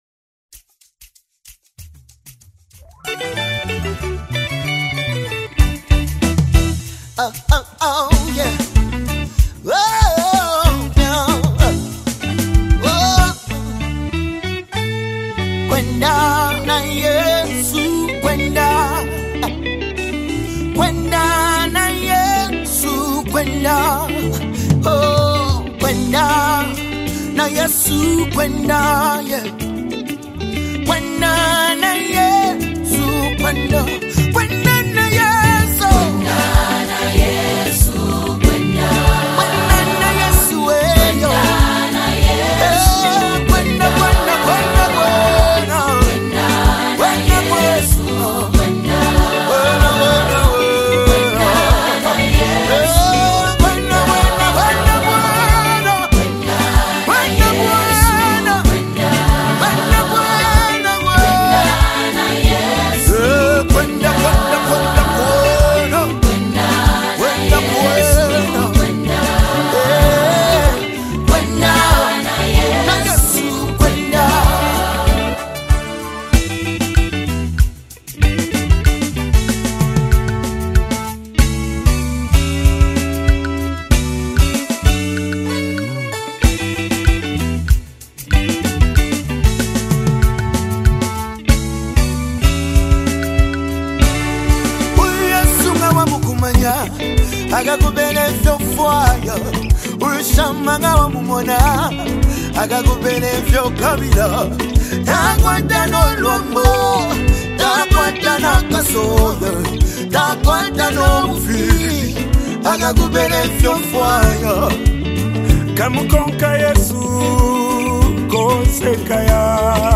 2025 ZAMBIA GOSPEL MUSIC
passionate vocals